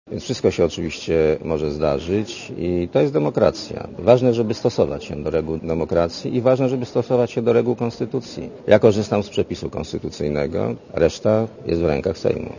Dla Radia Zet mówi premier Leszek Miller (49 KB)